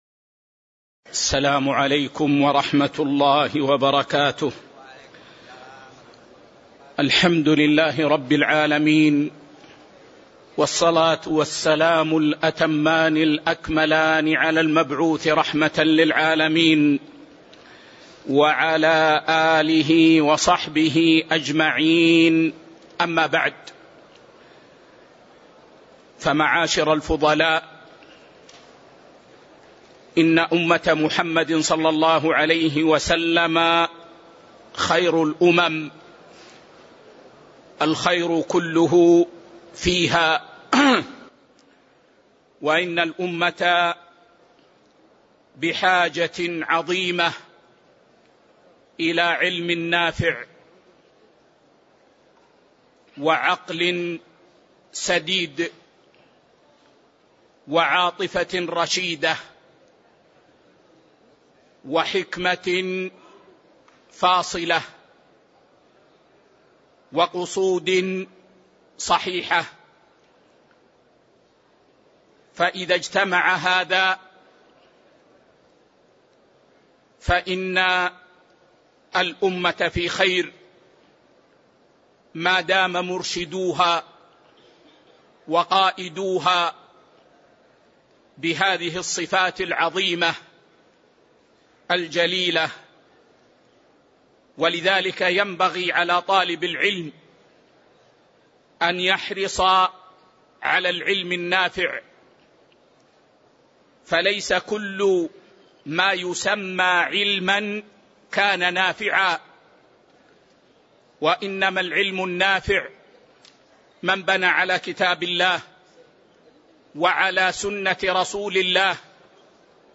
تاريخ النشر ٥ ذو القعدة ١٤٤٤ هـ المكان: المسجد النبوي الشيخ